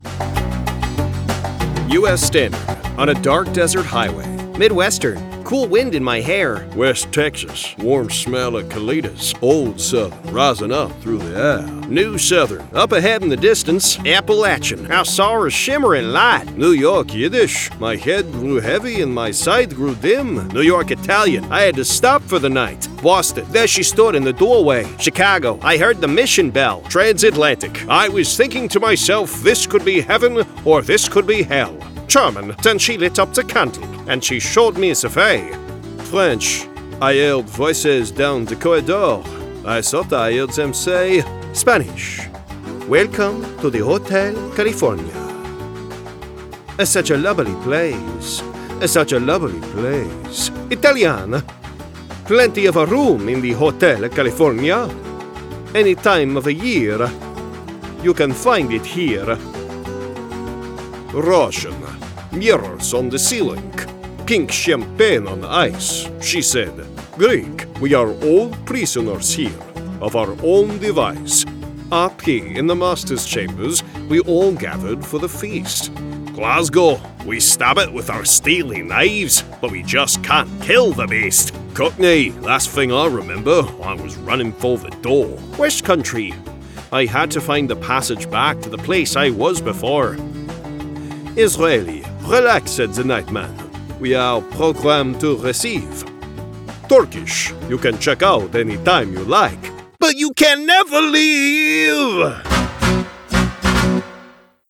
Accent Showreel
Male
Southern States
British RP
Cockney